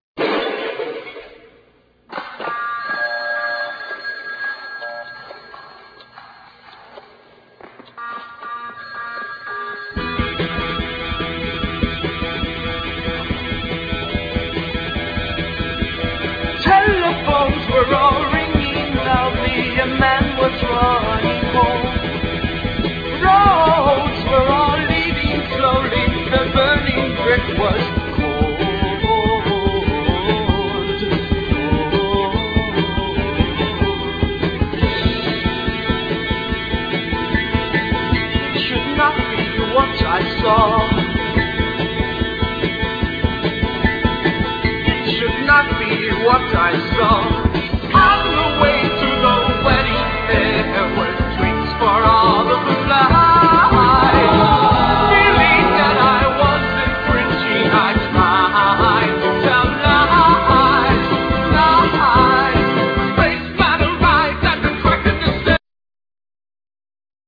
drums
tenor sax
trumpet
bass guitar